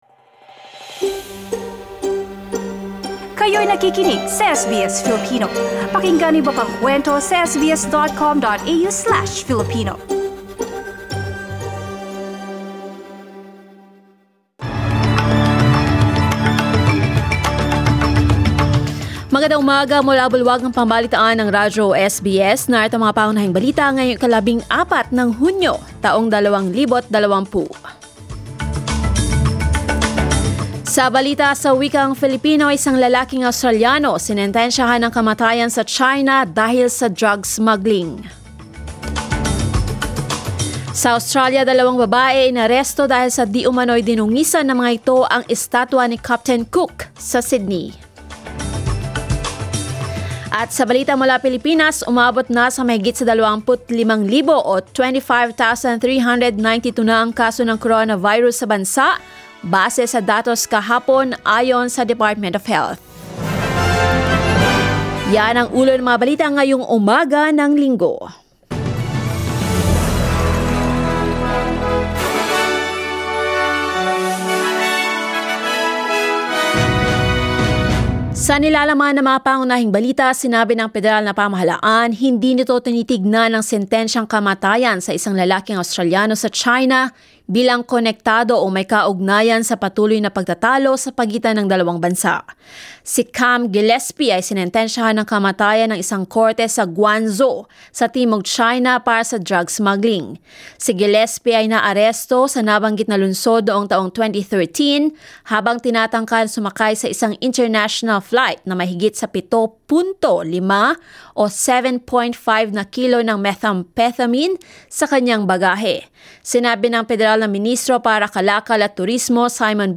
SBS News in Filipino, Sunday 14 June